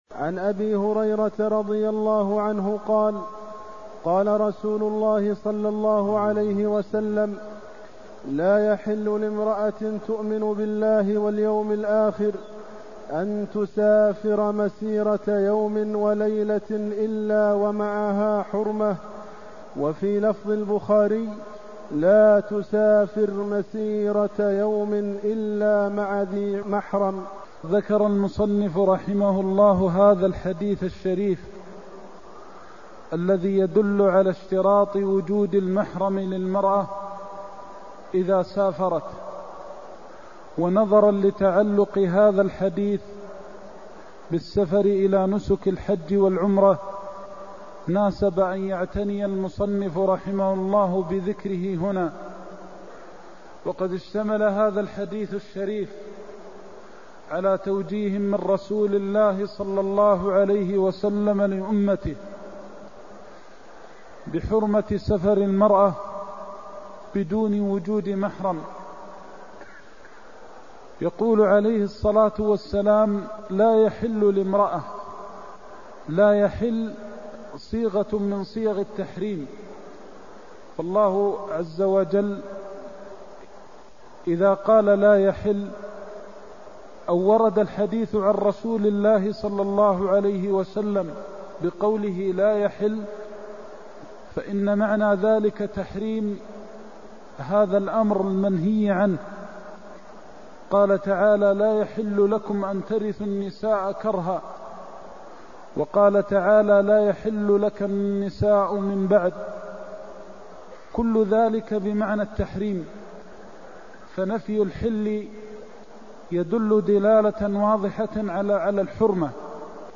المكان: المسجد النبوي الشيخ: فضيلة الشيخ د. محمد بن محمد المختار فضيلة الشيخ د. محمد بن محمد المختار لا تسافر المرأة يوماً ولا ليلة إلا معها ذي محرم (207)